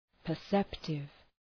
Προφορά
{pər’septıv}